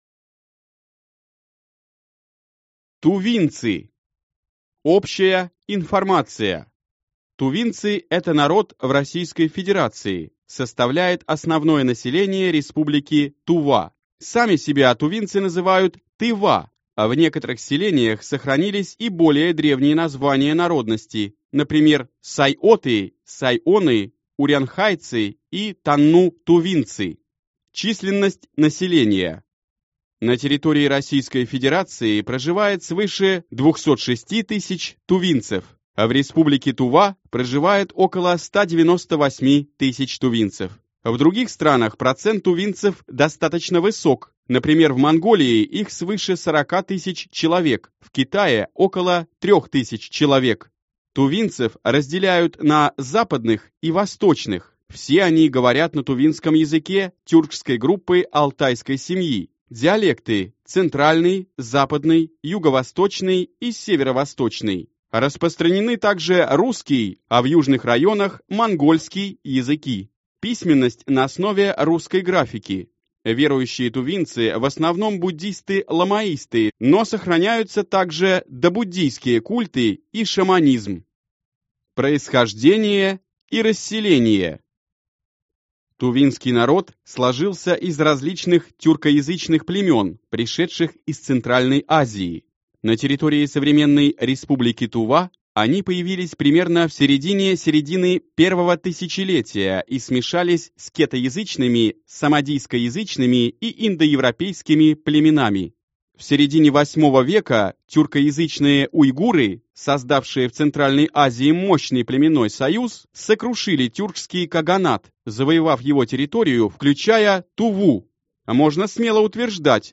Аудиокнига Народы России | Библиотека аудиокниг